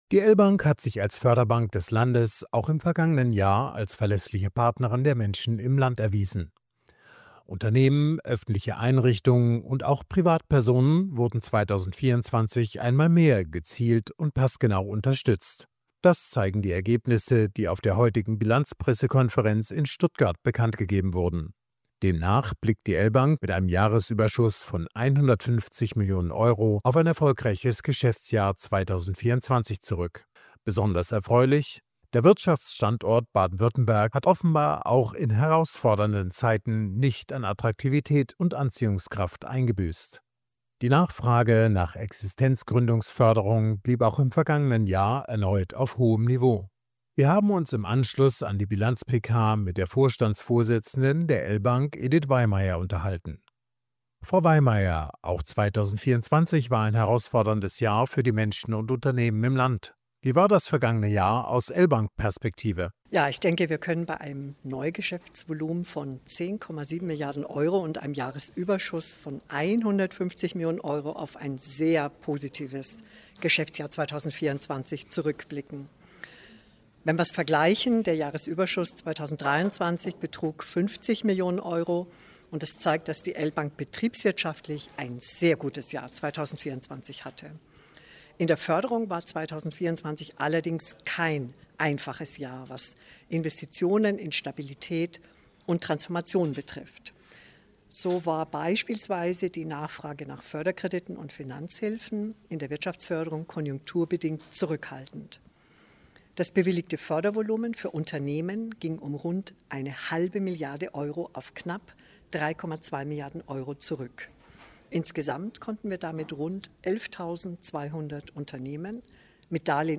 Sendefähige Interviews, Statements und O-Töne zu aktuellen Themen
Radio-Feature